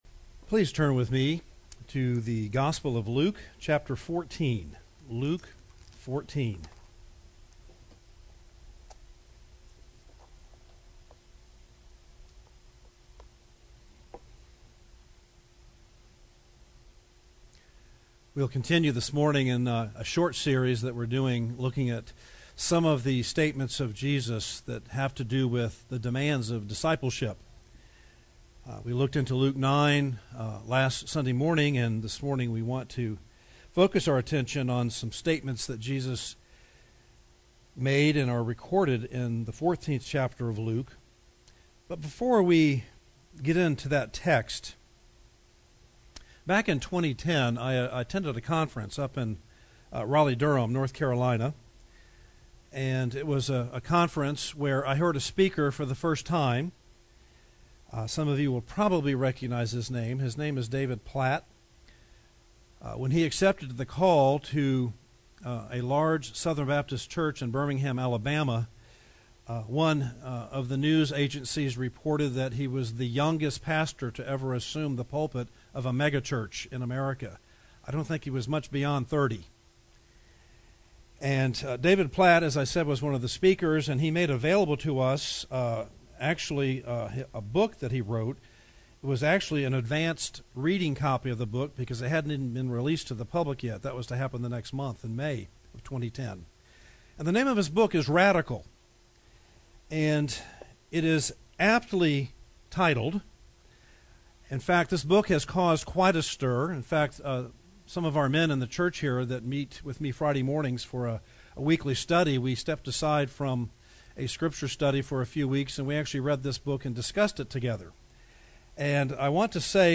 The Demands of Discipleship - Part II » The Chapel Church of Gainesville, Florida